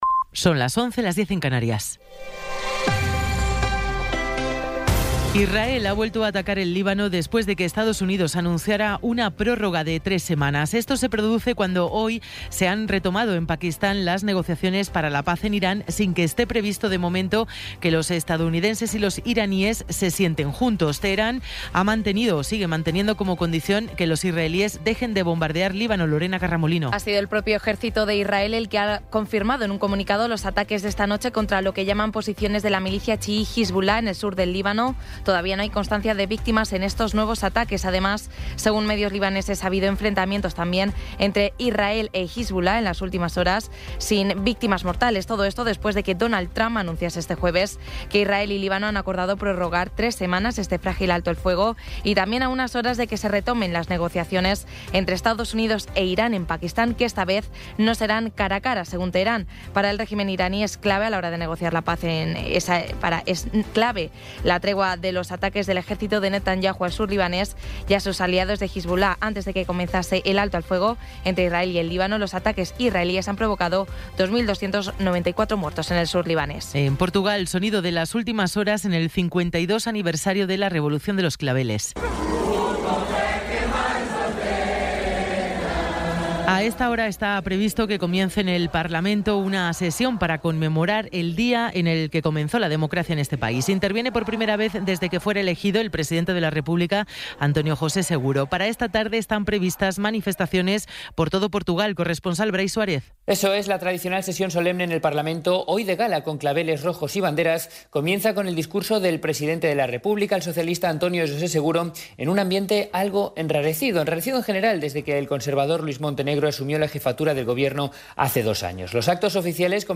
Resumen informativo con las noticias más destacadas del 25 de abril de 2026 a las once de la mañana.